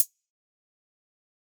PluGG hi hat (3).wav